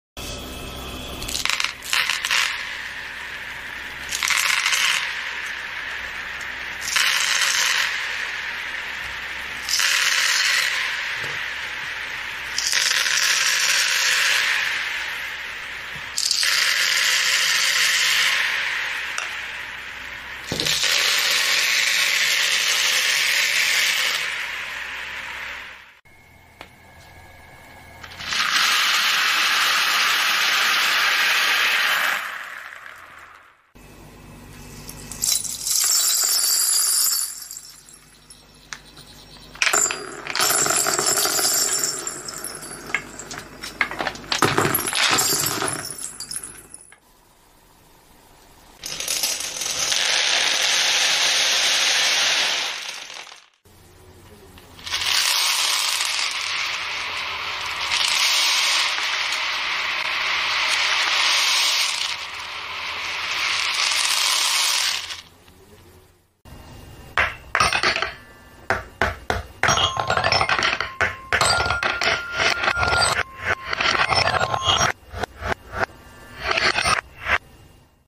⭐Oddly Satisfying video💯 Colored Beads